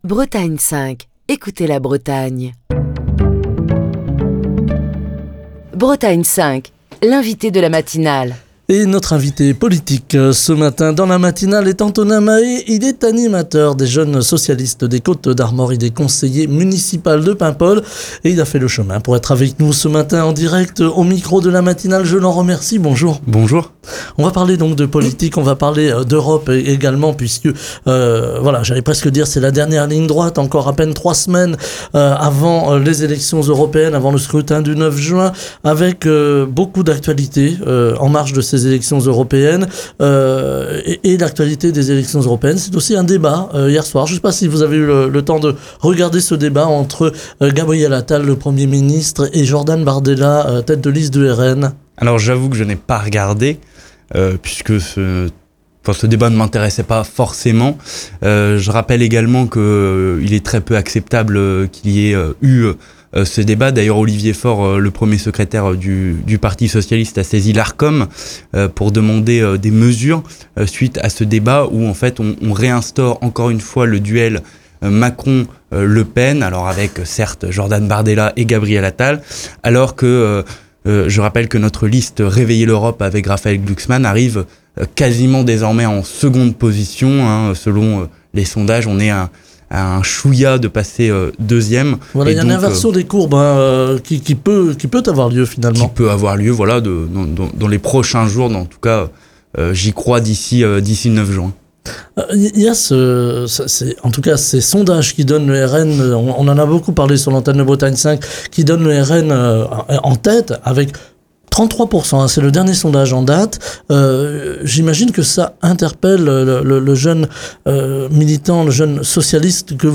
Antonin Mahé, animateur des Jeunes Socialistes des Côtes d'Armor, conseiller municipal de Paimpol est l'invité de la matinale de Bretagne 5, ce vendredi.